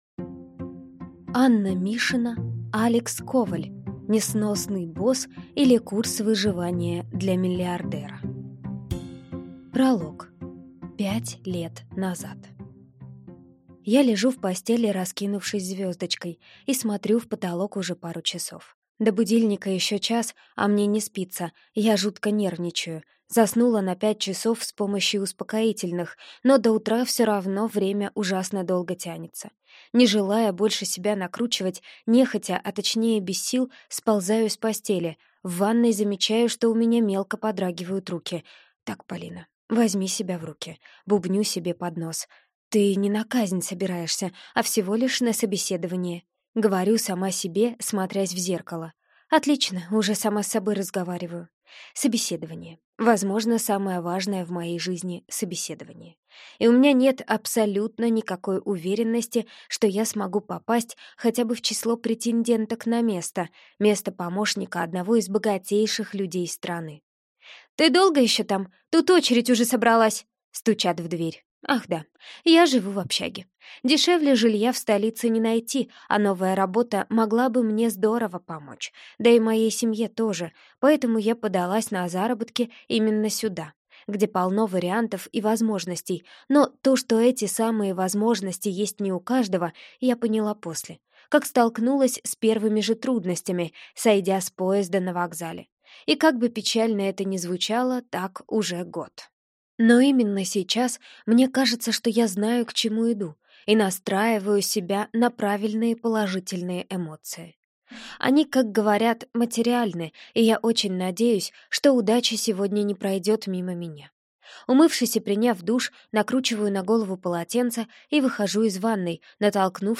Аудиокнига Несносный босс или курс выживания для миллиардера | Библиотека аудиокниг